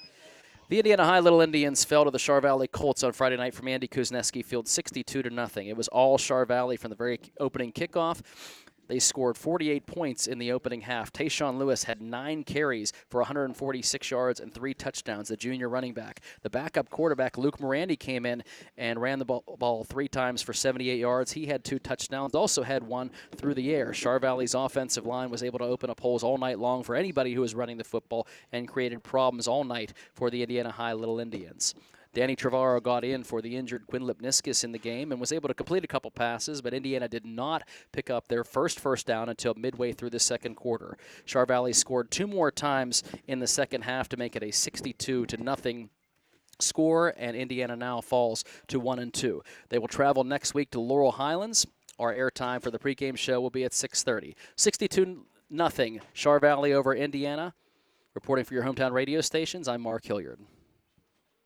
hsfb-indiana-vs-chartiers-valley-recap.wav